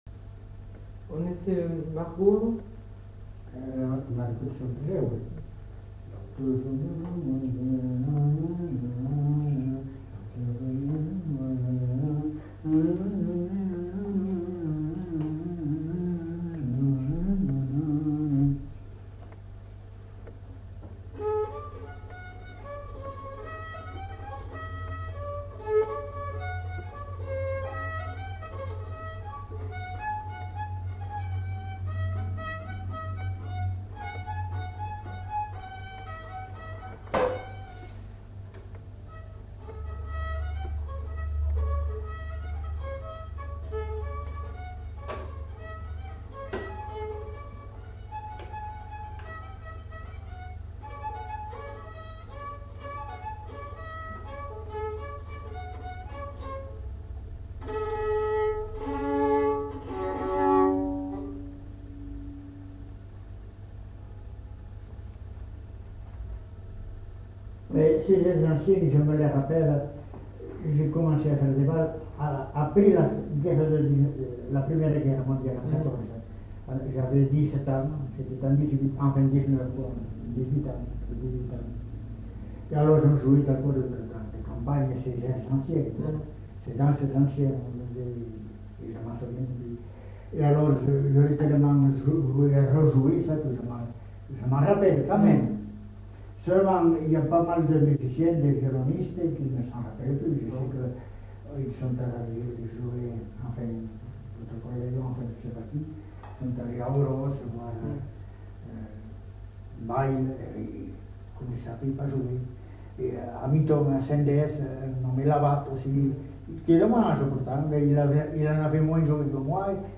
Aire culturelle : Bazadais
Lieu : Bazas
Genre : morceau instrumental
Instrument de musique : violon
Danse : rondeau